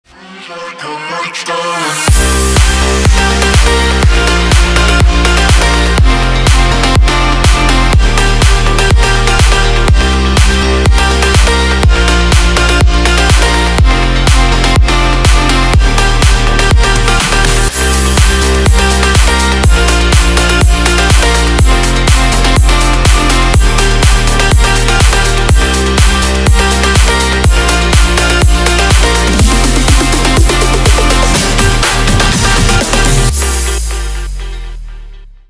dance
Style: Club House